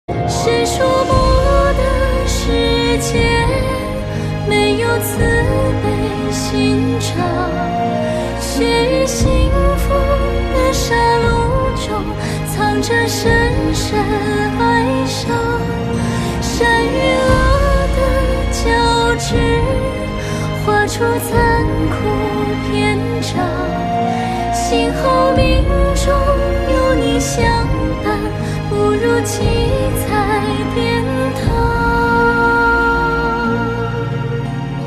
M4R铃声, MP3铃声, 华语歌曲 67 首发日期：2018-05-15 13:38 星期二